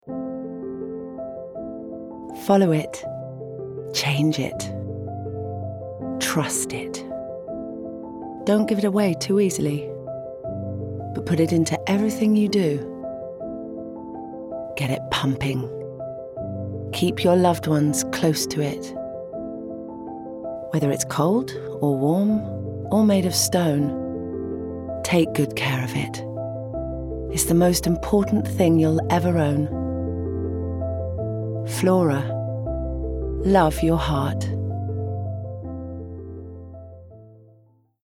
30/40's RP/Neutral, Warm/Reassuring/Engaging
Commercial Showreel